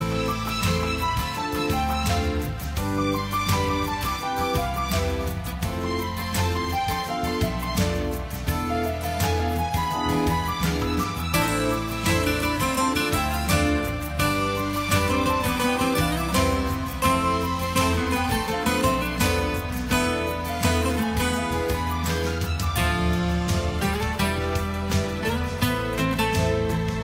• Качество: 256, Stereo
гитара
саундтреки
спокойные
без слов
красивая мелодия
Супер мелодия из турецкого сериала